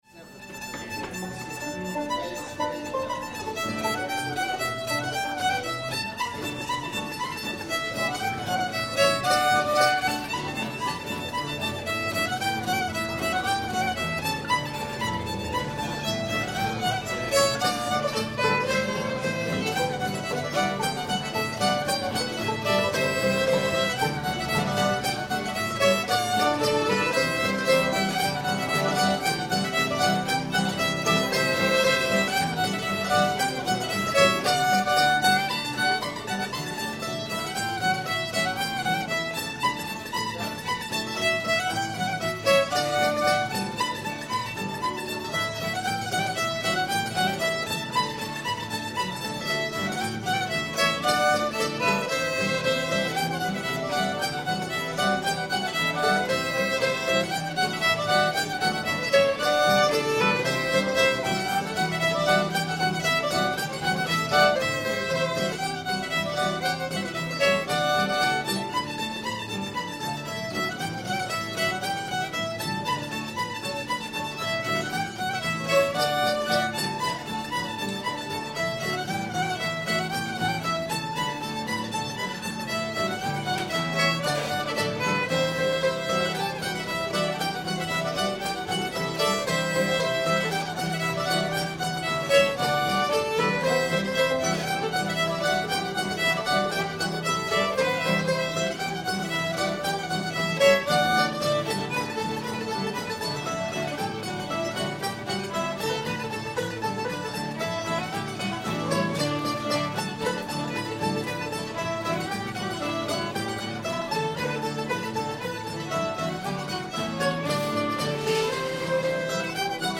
waiting for nancy [D]